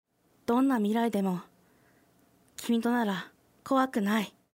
ボイス
女性